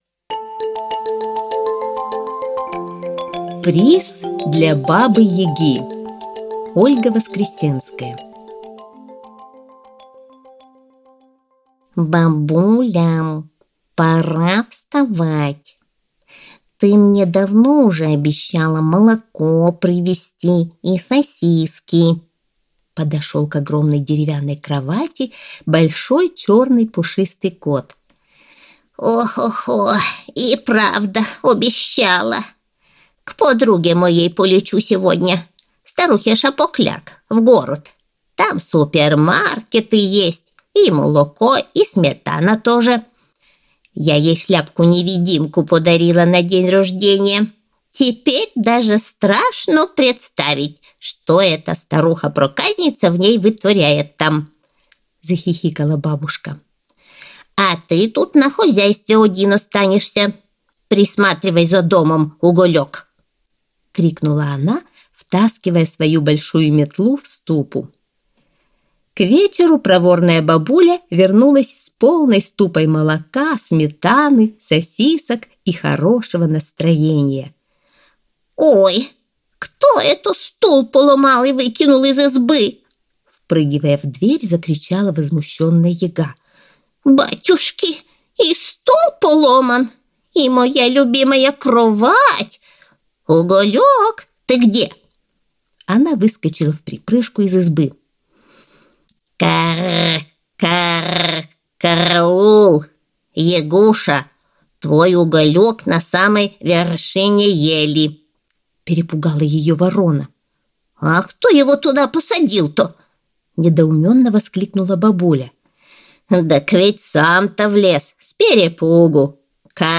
Аудиосказка «Приз Бабе Яге»